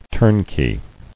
[turn·key]